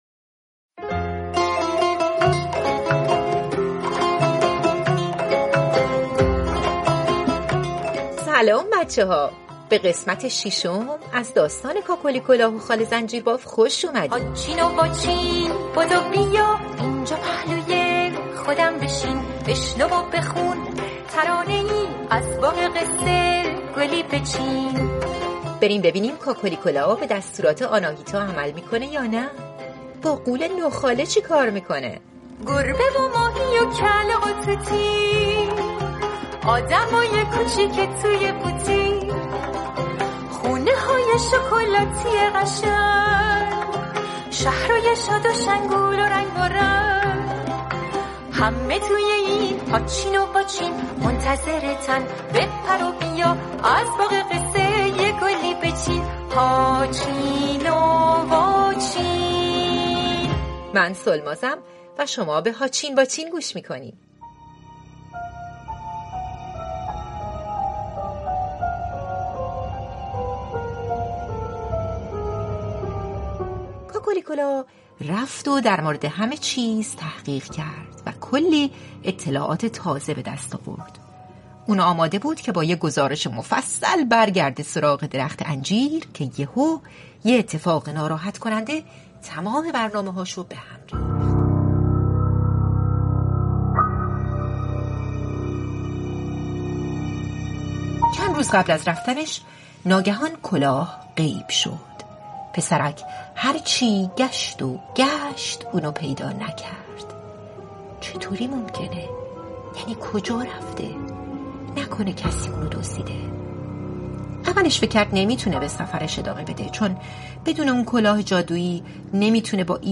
پادکست «هاچین واچین» اولین کتاب صوتی رادیو فردا، مجموعه داستان‌های کودکان است.